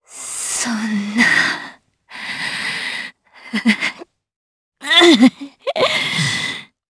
DarkFrey-Vox_Sad_jp_c.wav